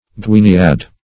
Search Result for " gwiniad" : The Collaborative International Dictionary of English v.0.48: Gwiniad \Gwin"i*ad\ (gw[i^]n"[i^]*[a^]d), n. [W. gwyniad a whiting, the name of various fishes, fr. gwyn white.]